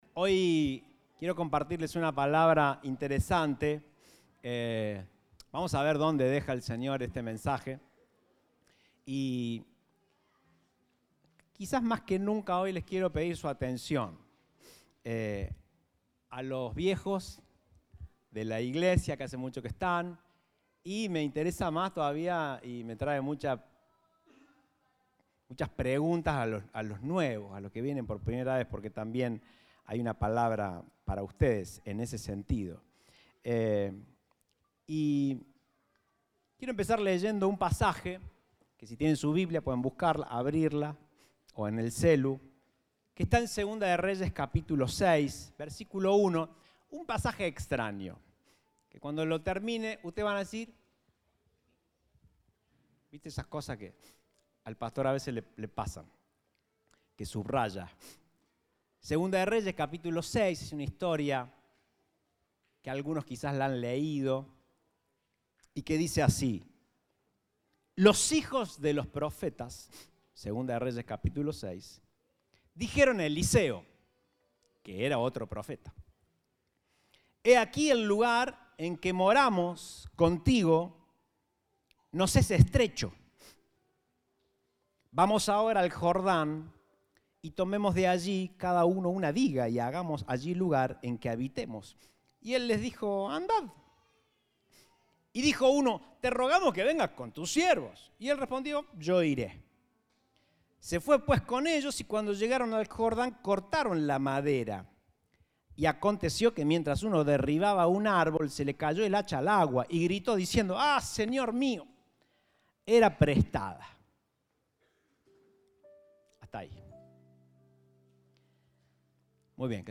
Compartimos el mensaje del Domingo 19 de Junio de 2022.